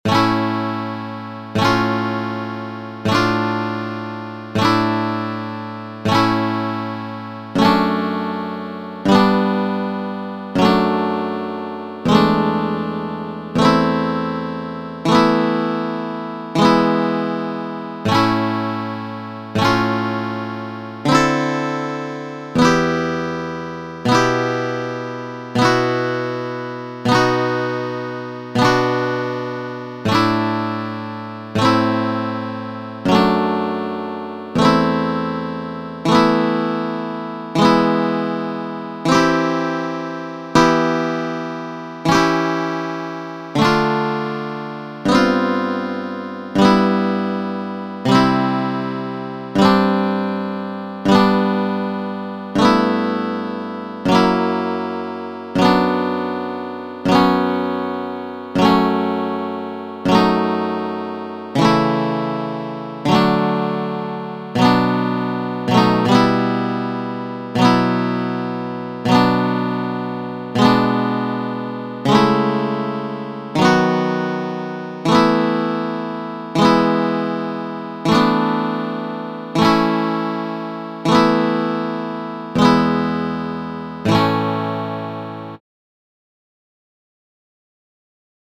Mi kompilis preskaŭ ĉiujn muzikaĵojn de Fernando Sor (fakte nur tri mankas ankoraŭ) kaj publikigis ilin pere de mia retpaĝo, per MIDI formato pere de programoj Mozart kaj TableEdit.